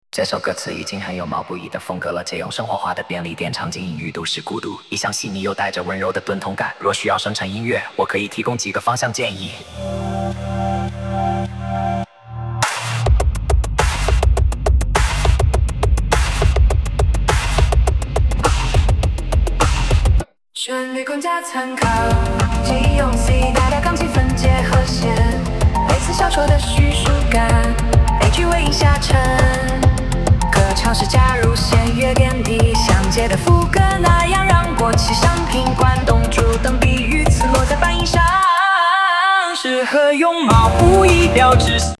- 主歌：建议用C大调钢琴分解和弦，类似《消愁》的叙述感，每句尾音下沉
- 副歌：可尝试加入弦乐垫底，像《借》的副歌那样让"过期商品""关东煮"等比喻词落在半音上
人工智能生成式歌曲